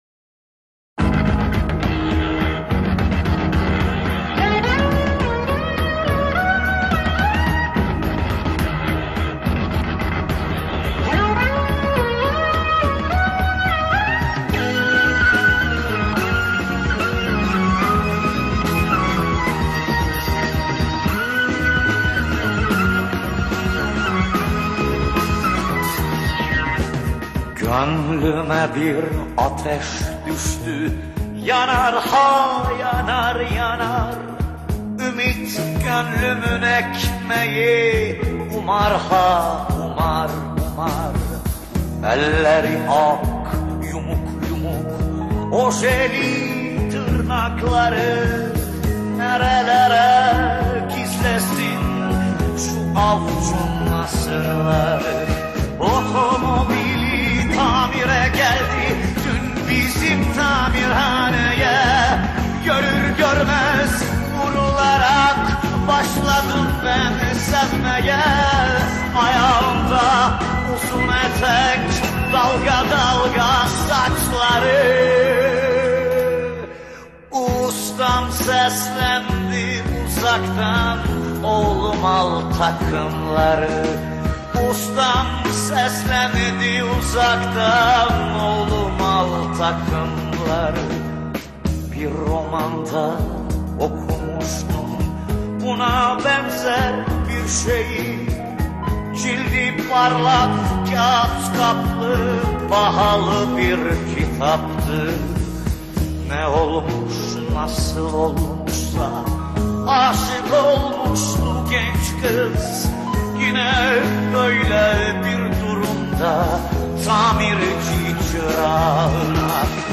Turkish Folk Music, Anatolian Rock, Turkish Pop